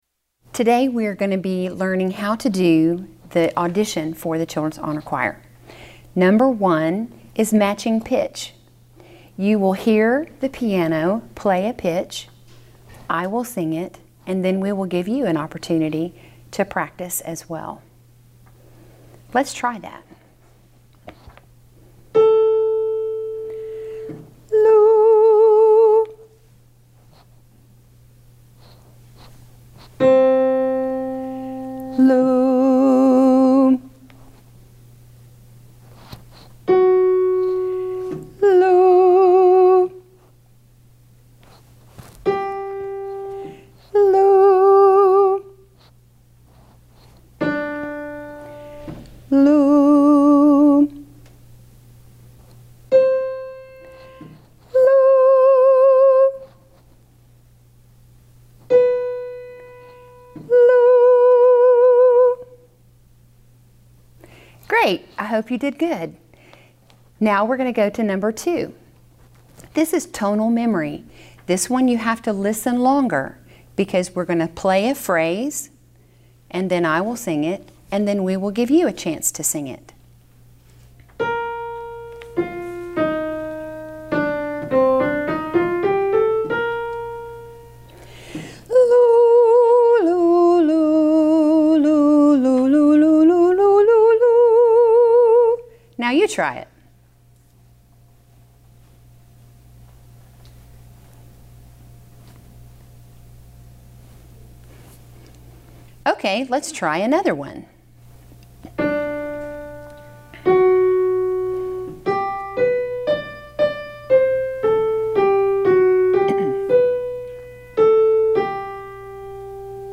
Practice Track 1, you will only record the matching pitch and phrase matching portion (cut the practice recording off at this point), and still sing your a cappella piece.